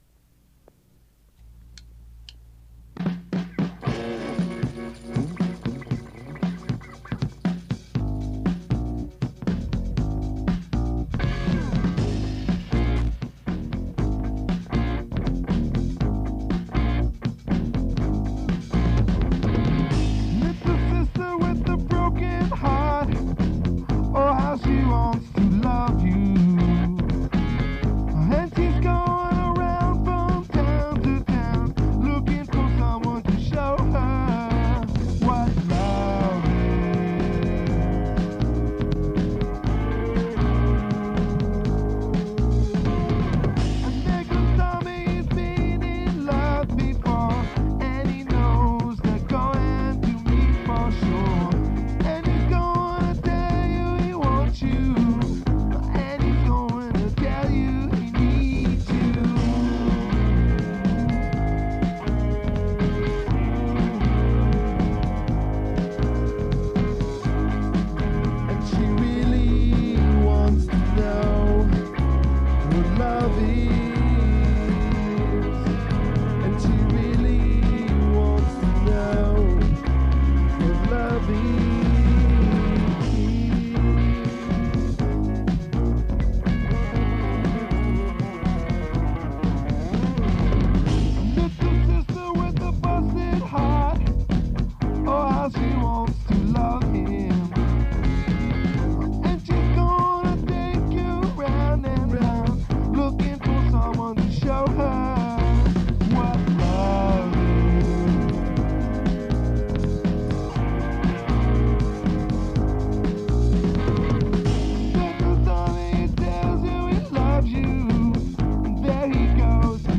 But this is funky.
on guitars. He turned it into a minor key somehow.